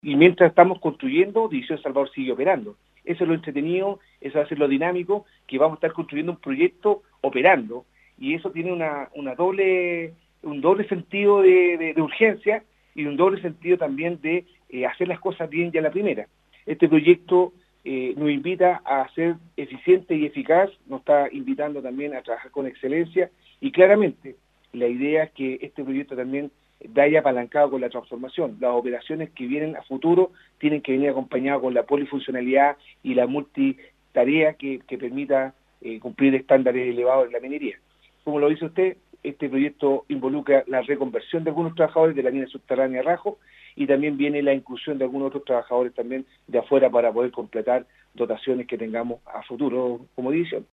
sostuvo un contacto telefónico con el equipo de prensa de Nostálgica